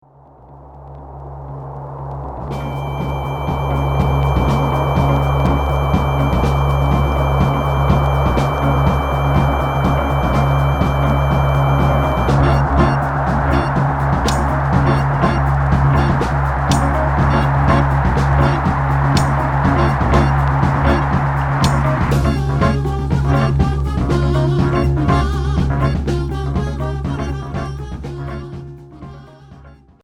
Free rock Unique 45t retour à l'accueil